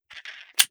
Foley
38 SPL Revolver - Spinning Barrel 001.wav